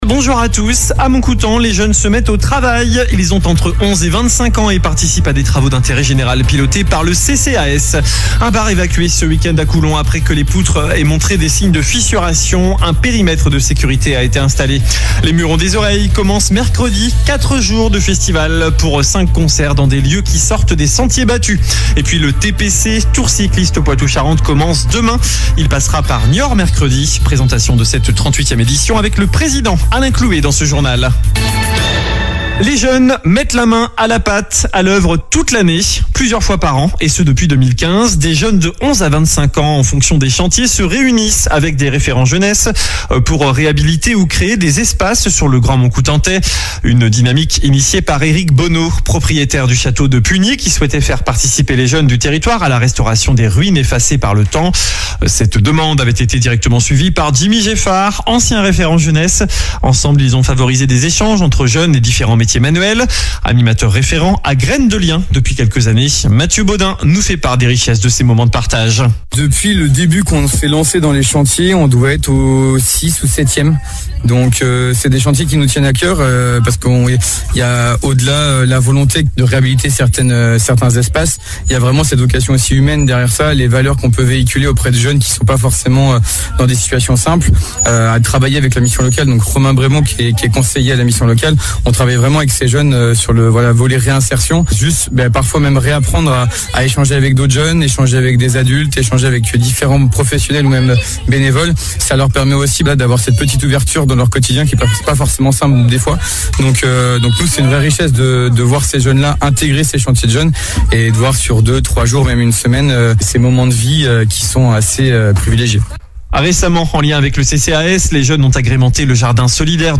JOURNAL DU LUNDI 19 AOÛT
infos locales 19 août 2024